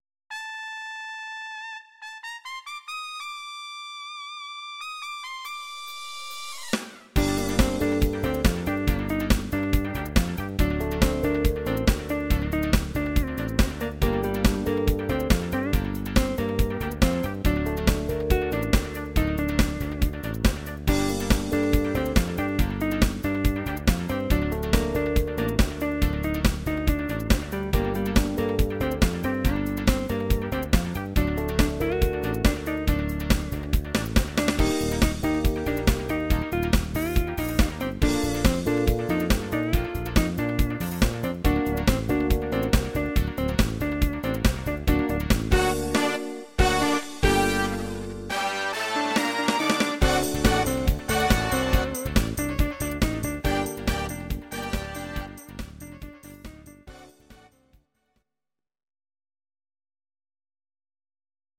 These are MP3 versions of our MIDI file catalogue.
Please note: no vocals and no karaoke included.
Your-Mix: Musical/Film/TV (834)